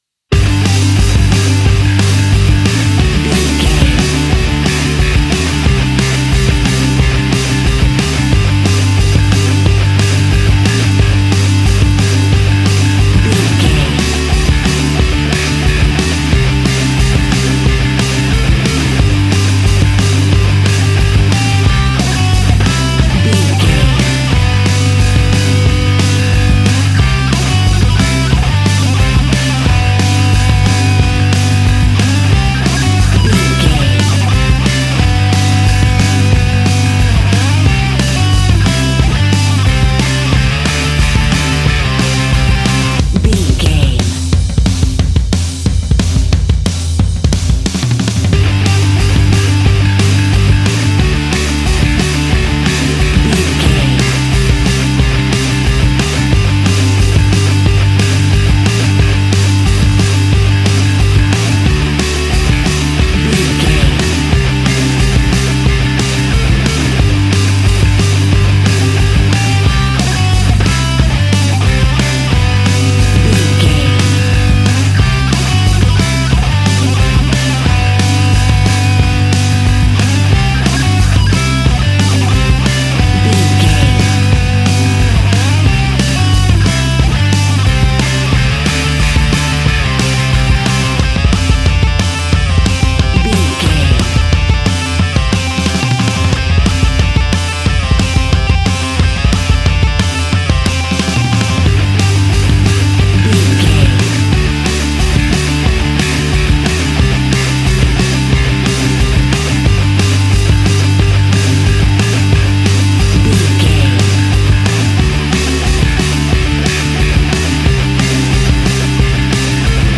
Epic / Action
Fast paced
Ionian/Major
hard rock
distortion
punk metal
instrumentals
Rock Bass
heavy drums
distorted guitars
hammond organ